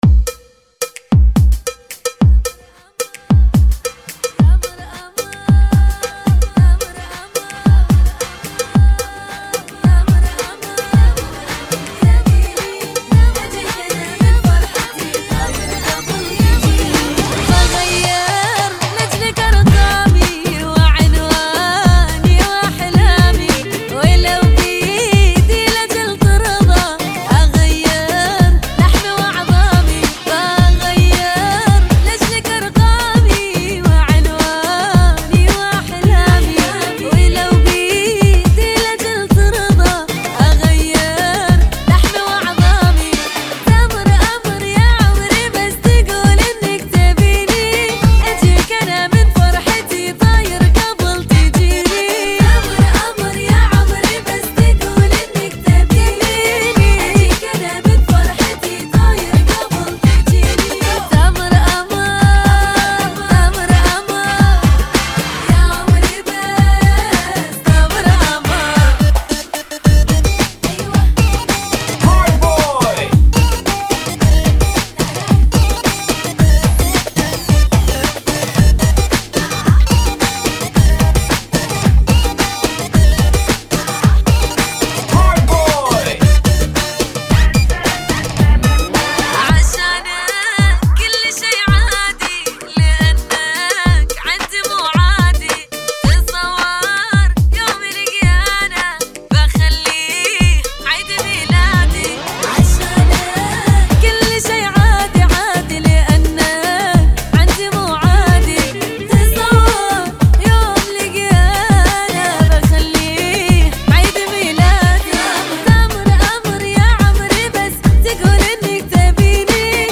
new funky [ 110 Bpm ]